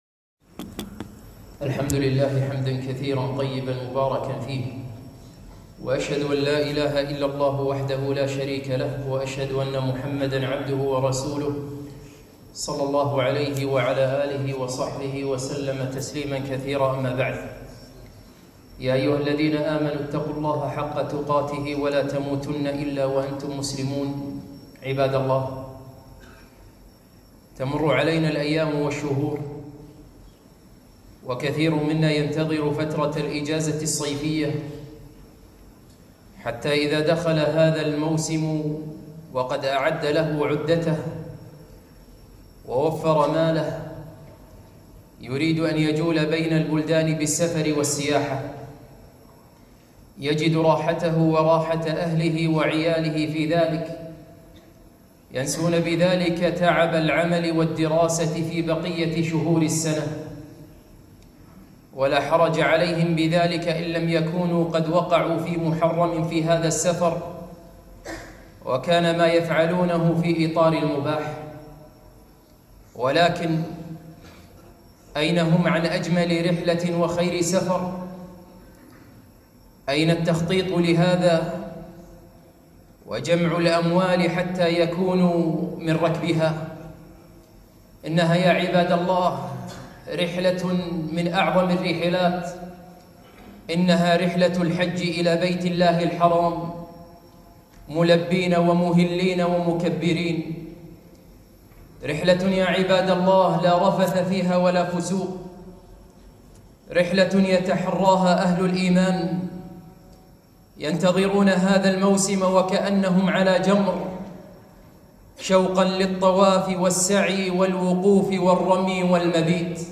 خطبة - ألا تحبون أن تكونوا معهم؟